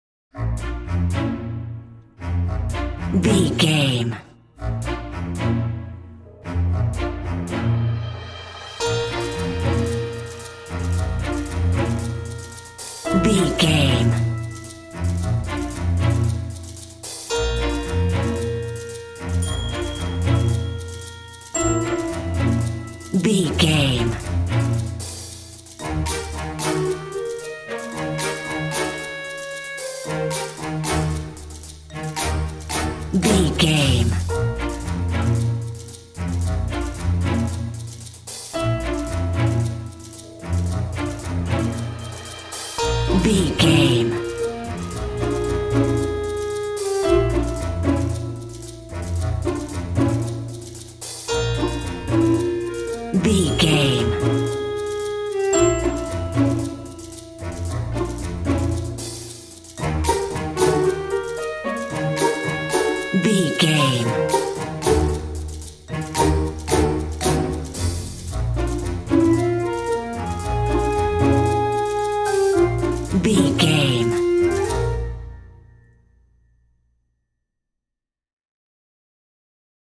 Uplifting
Lydian
B♭
flute
oboe
strings
orchestra
cello
double bass
percussion
silly
goofy
comical
cheerful
perky
Light hearted
quirky